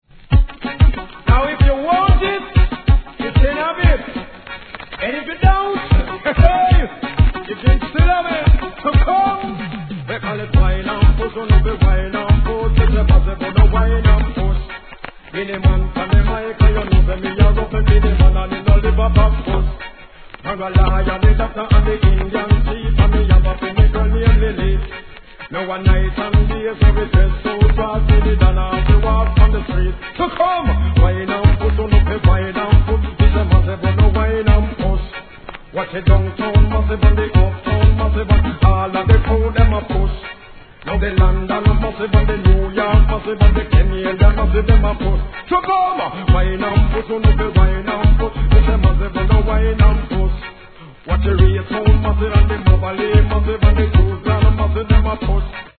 REGGAE
毎度分かりやい歌いまわしで、人気インディー・アーティスト!!